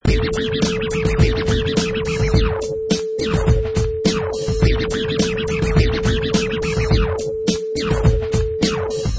Sound files: Vinyl Crazy Scratch 105 bpm with beat 1
Professional killer vinyl scratch on top of hip hop beat, perfect for sampling, mixing, music production, timed to 105 beats per minute
Product Info: 48k 24bit Stereo
Category: Musical Instruments / Turntables
Try preview above (pink tone added for copyright).
Vinyl_Crazy_Scratch_105_bpm_with_beat_1.mp3